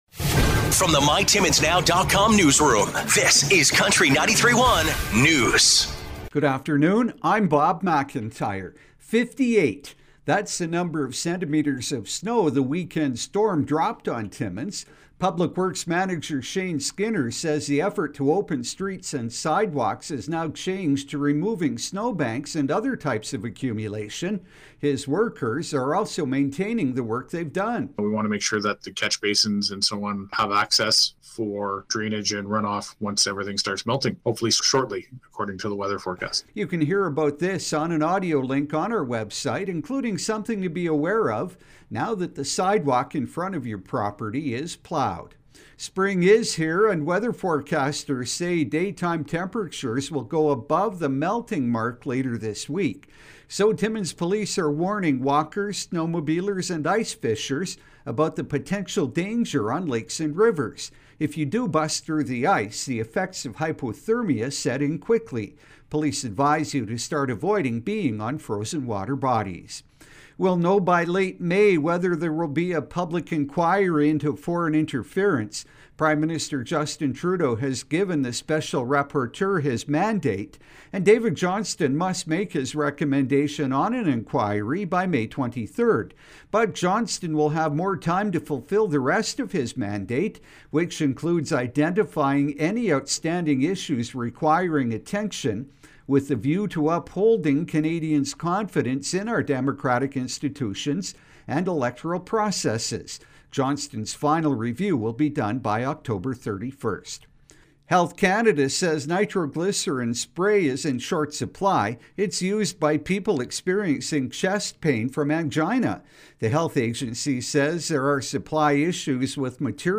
5:00pm Country 93.1 News – Tues., March 21, 2023